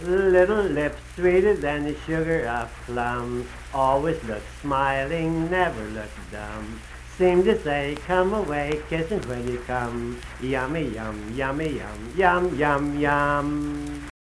Unaccompanied vocal music
Verse-refrain 1(4). Performed in Hundred, Wetzel County, WV.
Children's Songs
Voice (sung)